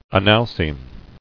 [a·nal·cime]